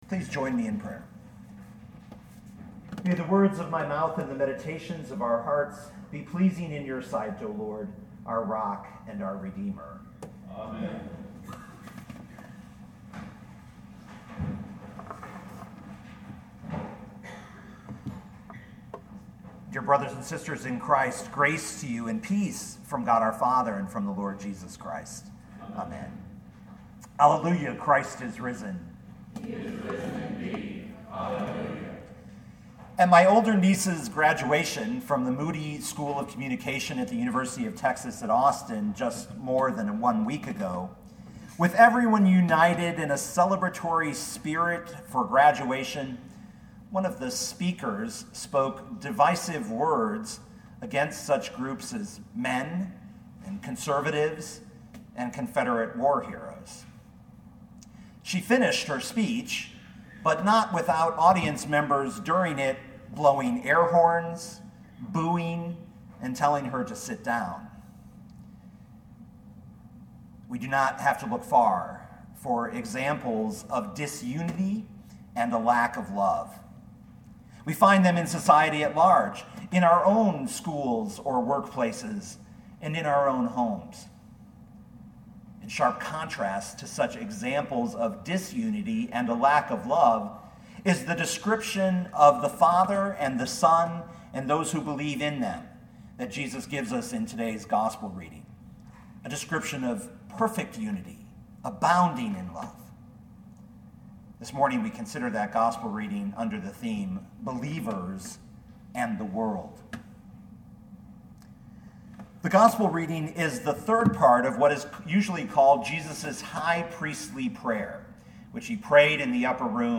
2019 John 17:20-26 Listen to the sermon with the player below, or, download the audio.